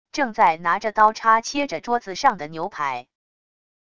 正在拿着刀叉切着桌子上的牛排wav音频